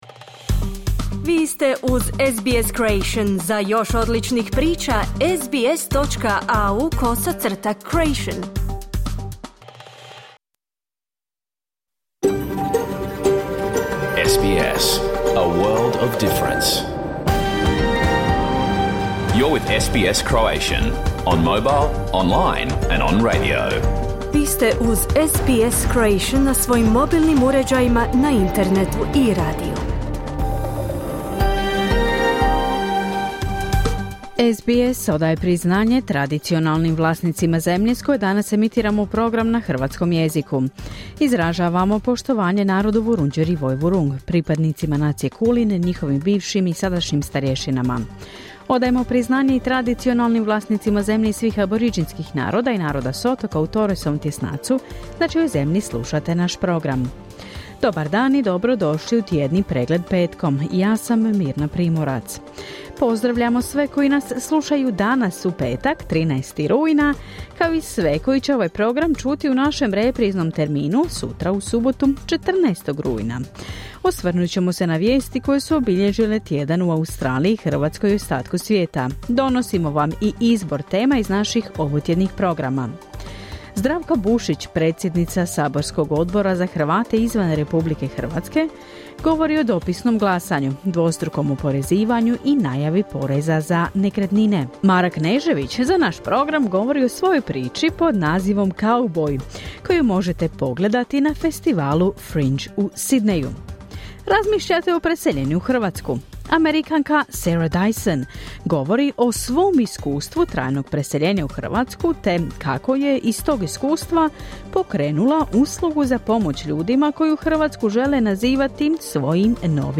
Tjedni izbor vijesti i aktualnosti iz Australije, Hrvatske i svijeta. Emitirano uživo na radiju SBS1, u petak, 13. rujna, u 11 sati po istočnoaustralskom vremenu.